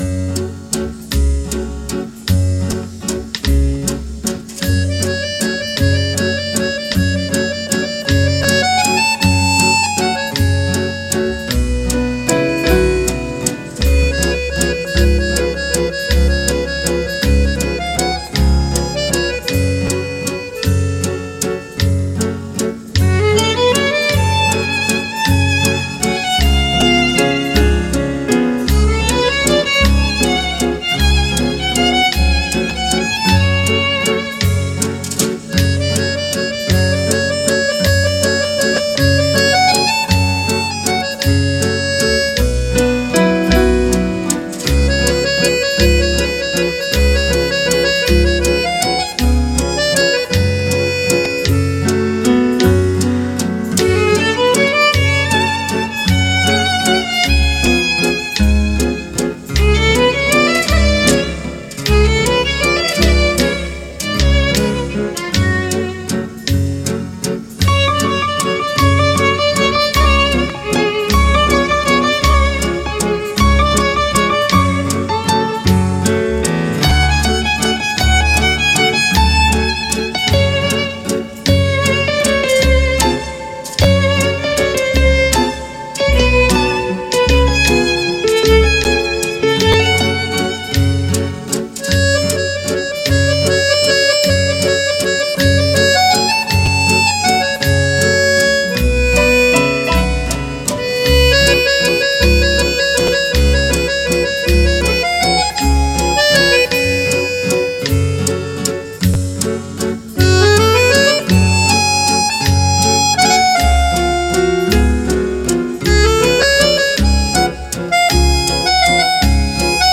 Mέτρο τριών τετάρτων εικόνα
Στο βαλς οι χτύποι τονίζονται σταθερά ανά τρεις.